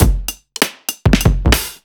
OTG_Kit9_Wonk_130b.wav